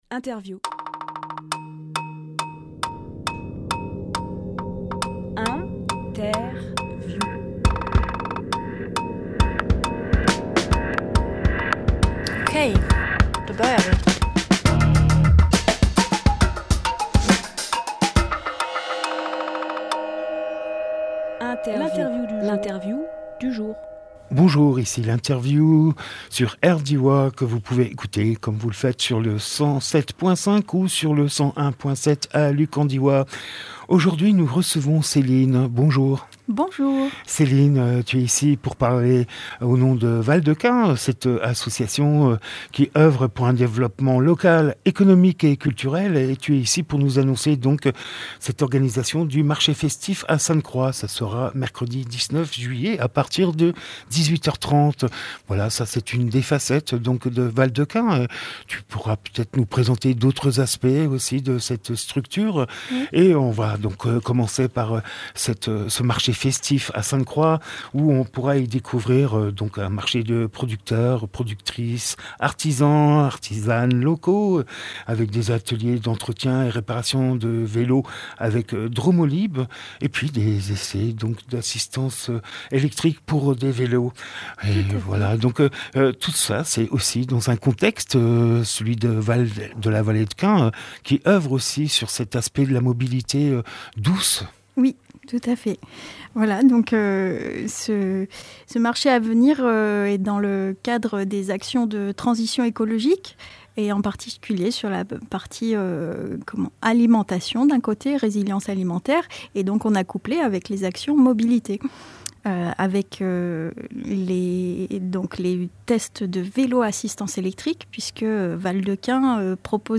Emission - Interview Le Marché festif à Sainte-Croix Publié le 13 juillet 2023 Partager sur…
12.07.23 Lieu : Studio RDWA Durée